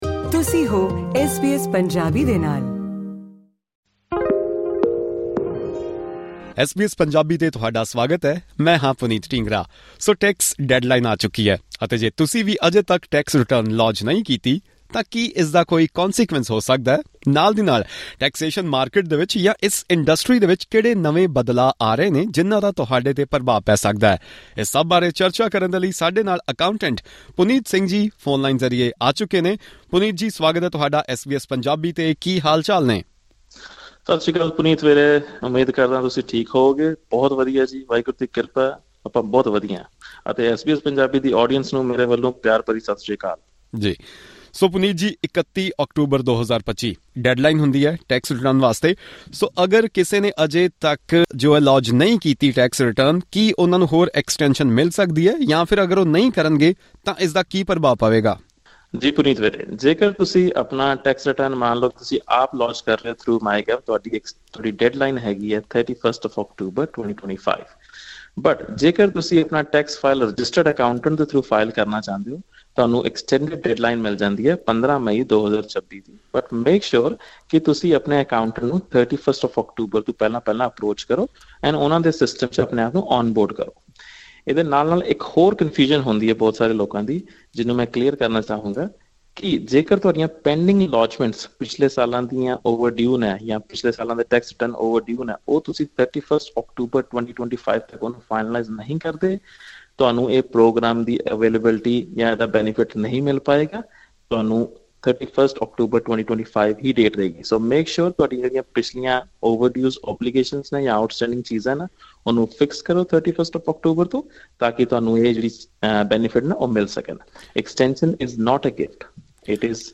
ਪੂਰੀ ਗੱਲਬਾਤ ਸੁਣੋ ਇਸ ਪੌਡਕਾਸਟ ਰਾਹੀਂ...